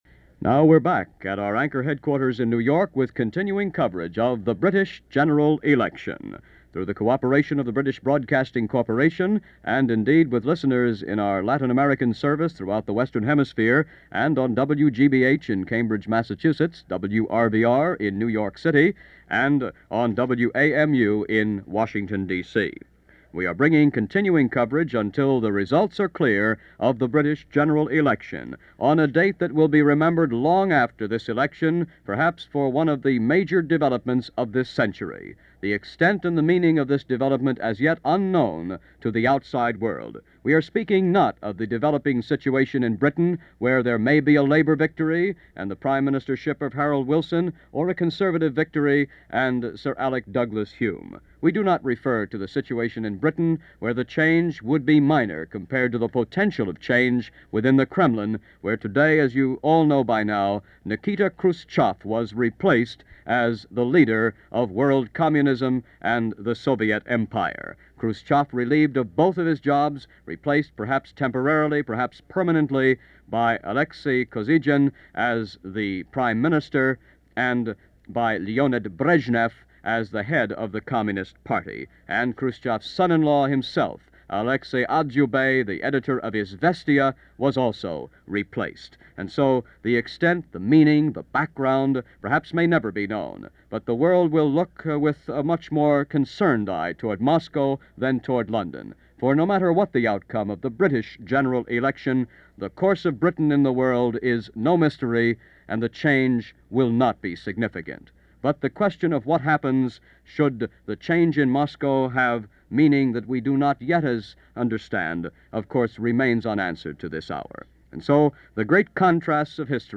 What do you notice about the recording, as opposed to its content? Election '64: Britain Votes - October 15, 1964 - Election results as broadcast by BBC World Service to the U.S.